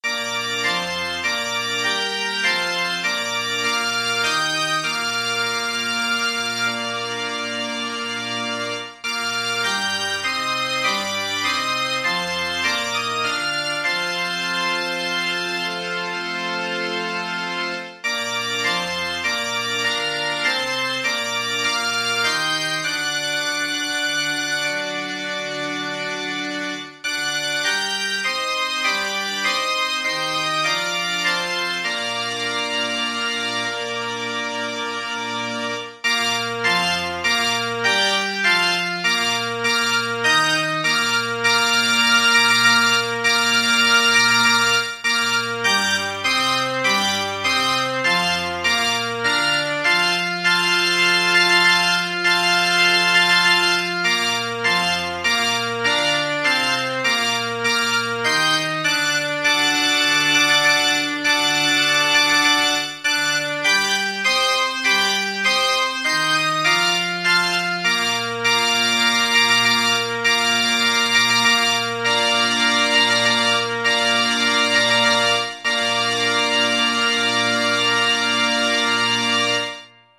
Maestoso
9/4 (View more 9/4 Music)
Classical (View more Classical Organ Music)